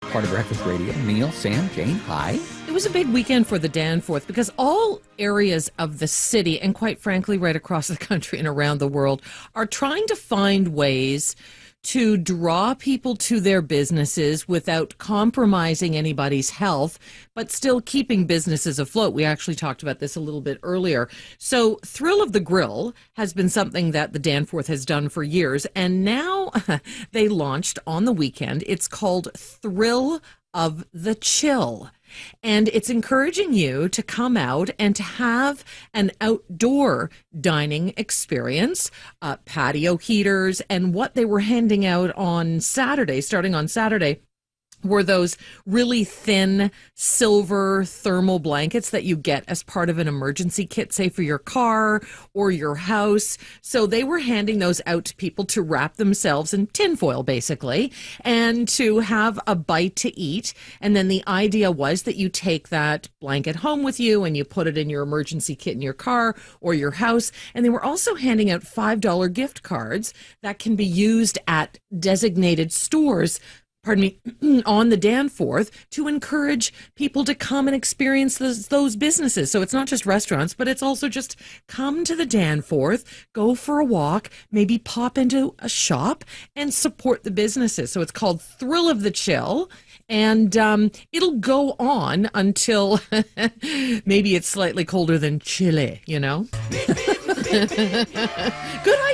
Attached is a clip from Zoomer Breakfast Radio this morning.